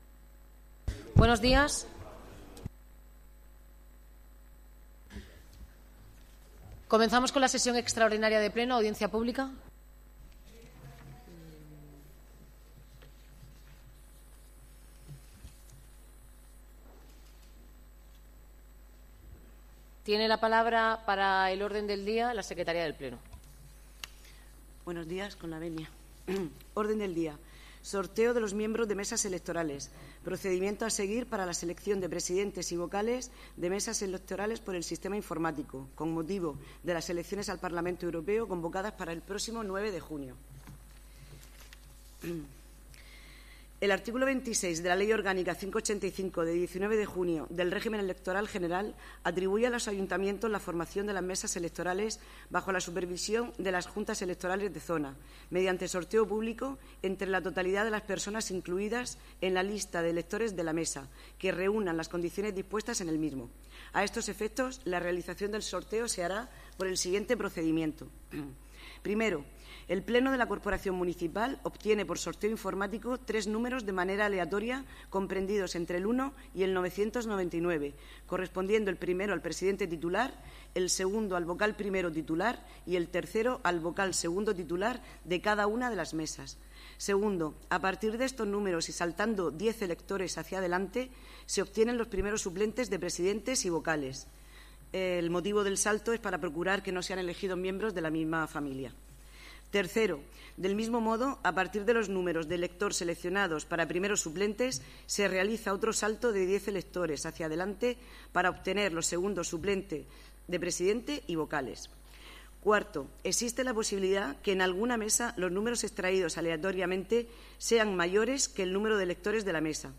Pleno extraordinario para el sorteo de las mesas electorales de los comición europeos del 9 de junio
El pleno de la corporación municipal se ha reunido en sesión extraordinaria este lunes 13 de mayo bajo la presidencia de la alcaldesa, Noelia Arroyo, para llevar a cabo el sorteo público de los integrantes de las mesas electorales de cara a las comicios al Parlamento Europeo del domingo 9 de junio, a las que están llamados a votar 157.292 personas empadronadas en Cartagena.